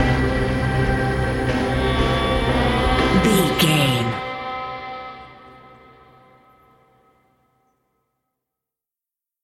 Thriller
Aeolian/Minor
synthesiser
percussion
tension
ominous
dark
suspense
haunting
creepy